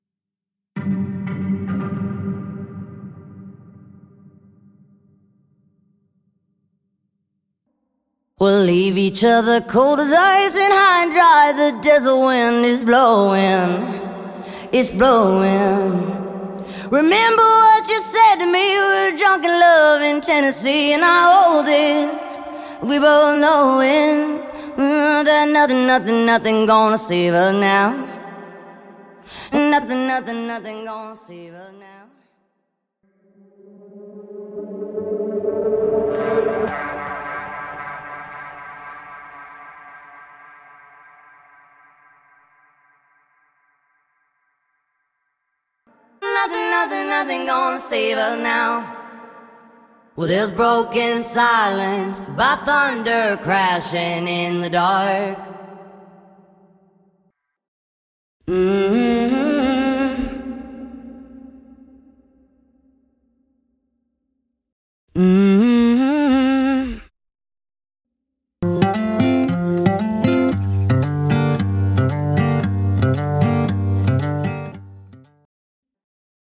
Studio Acoustic Guitar Stem
Studio Leading Dry Vocals Stem
Studio Piano Stem
Studio Strings Stem